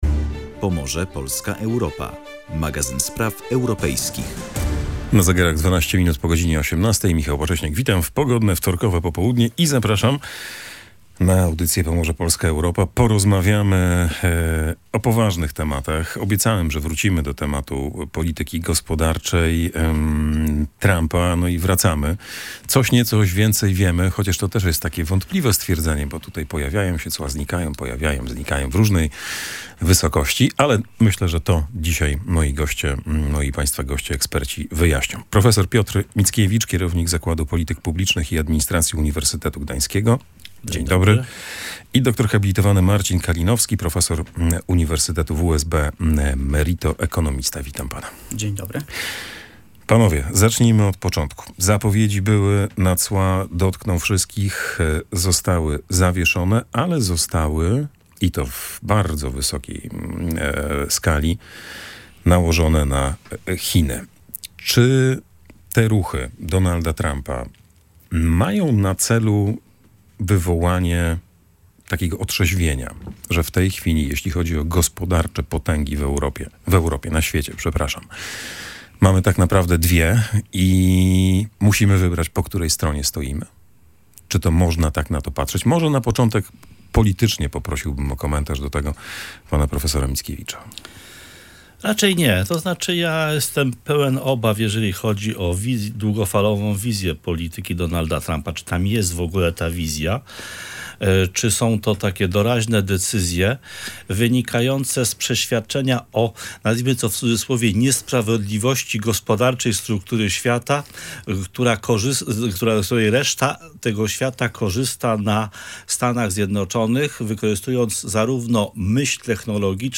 Rozmowę